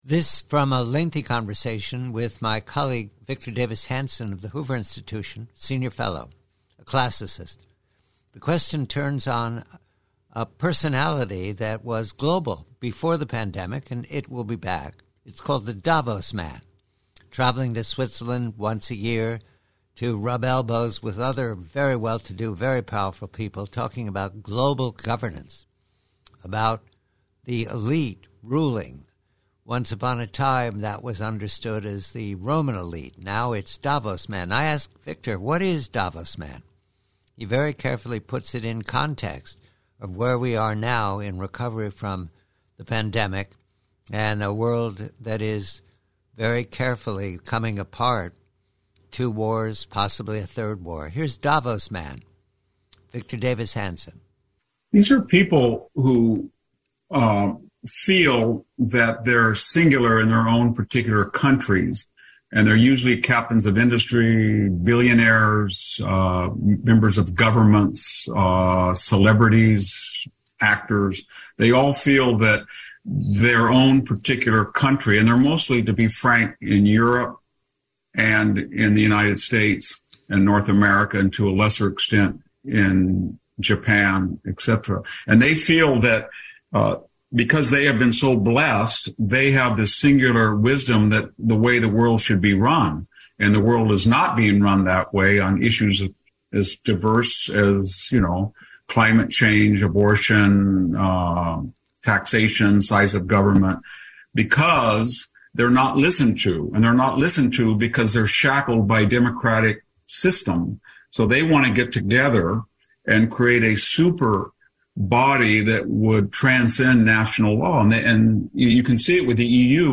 PREVIEW: From a much longer conversation with Hoover Senior Fellow Victor David Hanson about the disorienting assumptions of Amerian leadership, elite, media and academia. Here Victor David Hanson describes the strange conduct of the Davos Man.